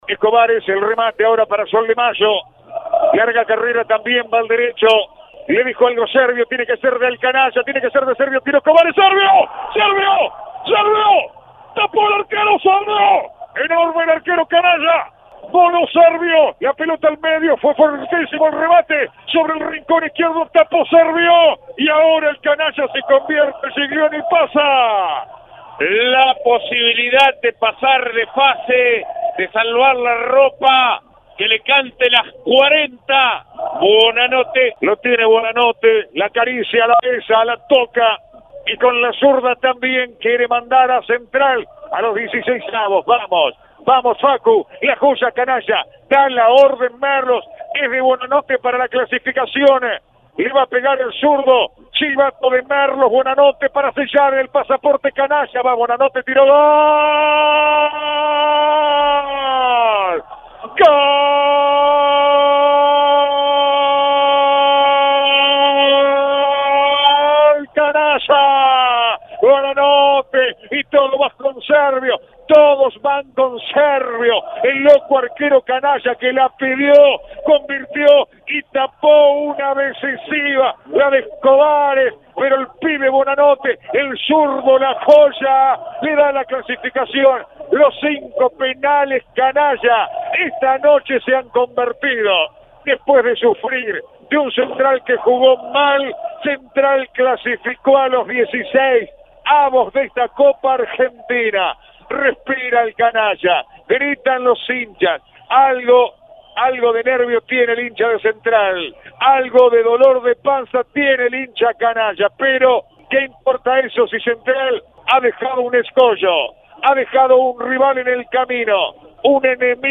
Después de empatar 1 a 1 en el tiempo reglamentario, el "Canalla" avanzó por penales ante el conjunto del Federal A. Reviví el gol de Gamba y la definición por penales relatados